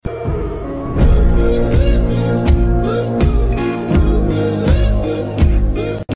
The electrifying Mercedes Benz. Sleek, dynamic, sound effects free download